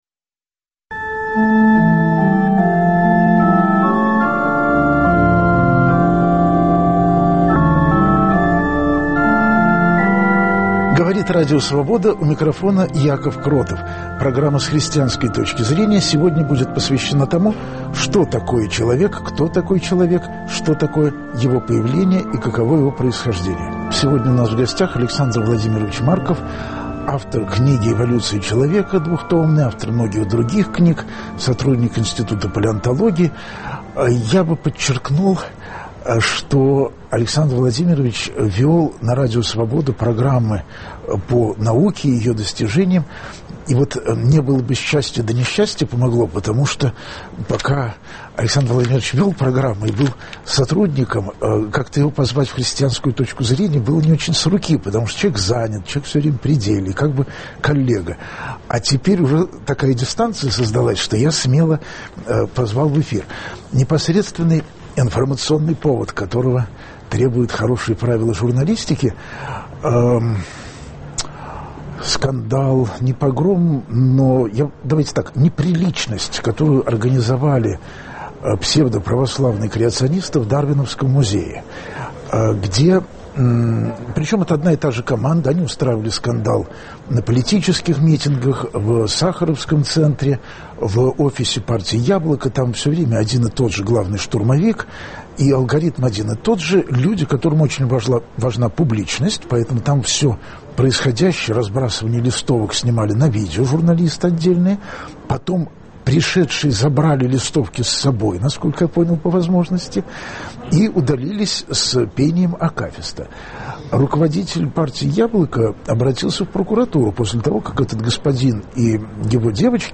Где проходит граница между научным и христианским пониманием человека? Гость программы - биолог Александр Марков.